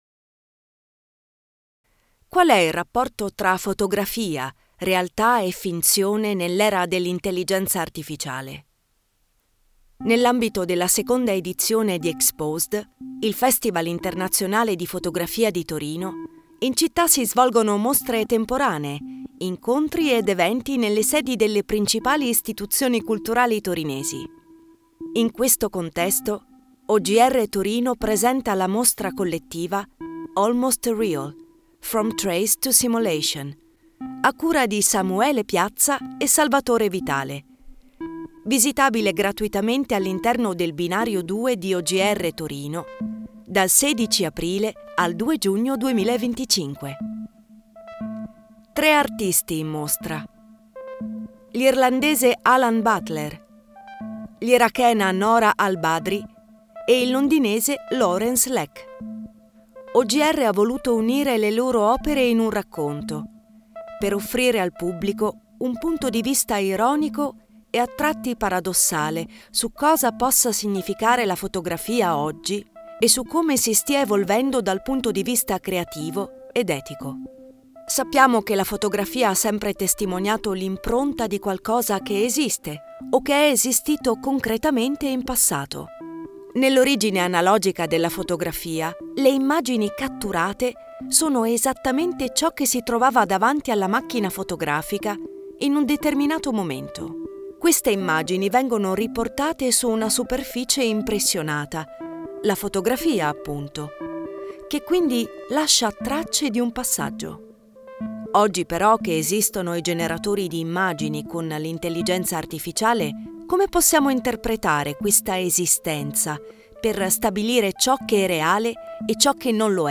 musiche e sound design di NoMad Studio
OGR_Audiodescrizione_Mostra_EXPOSED_2025_FromTraceToSimulation_DEF.mp3